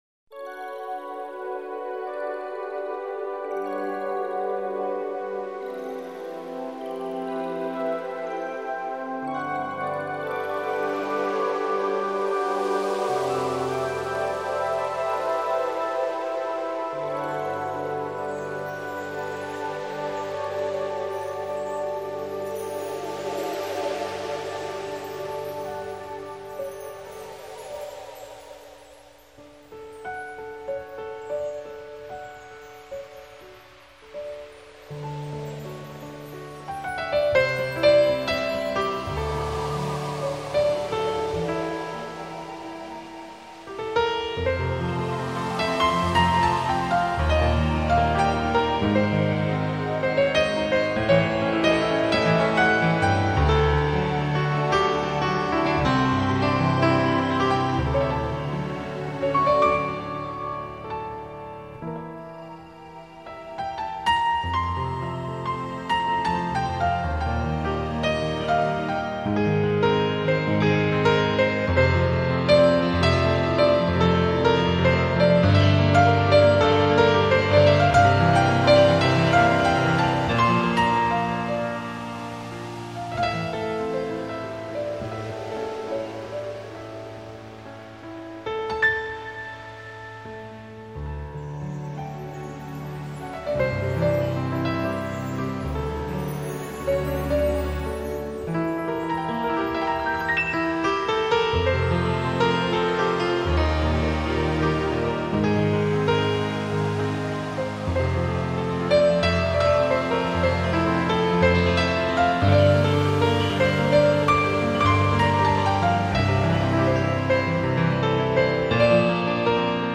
营造出泛着银晖的浪漫情境。